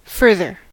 further: Wikimedia Commons US English Pronunciations
En-us-further.WAV